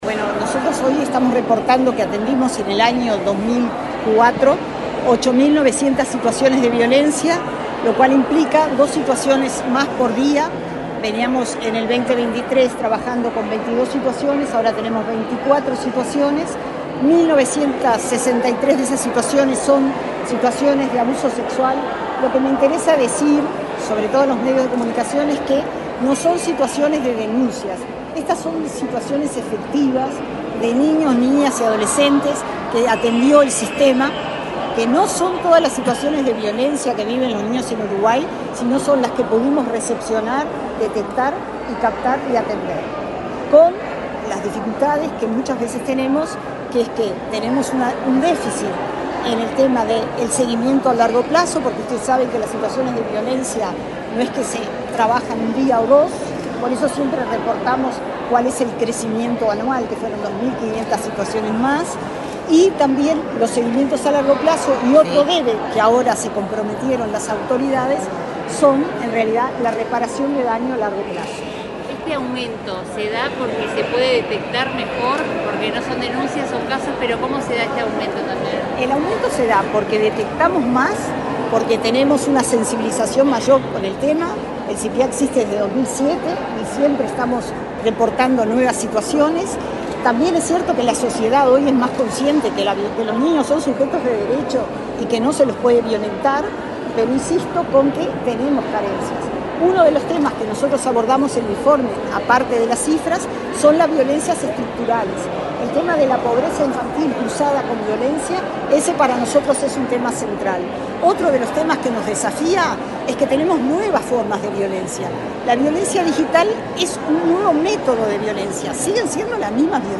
Declaraciones de la directora del Sipiav, María Elena Mizrahi